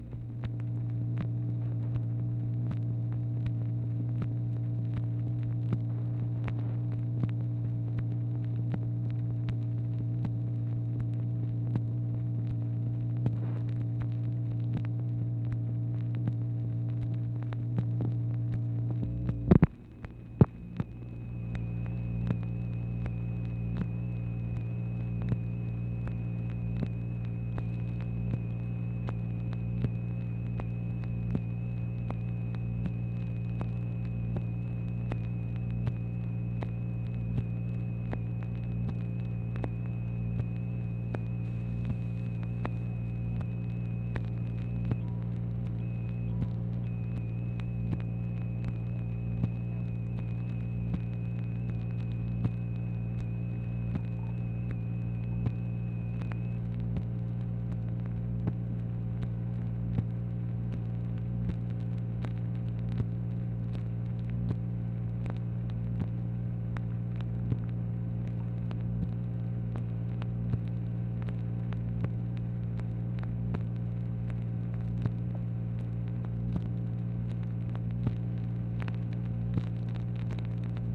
MACHINE NOISE, March 28, 1964